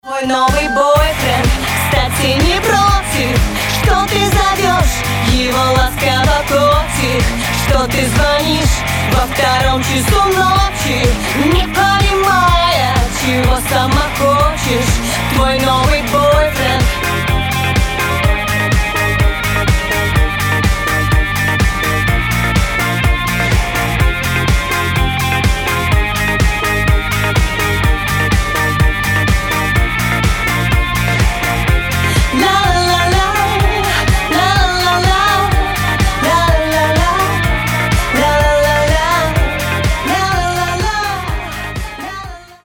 поп
женский вокал
dance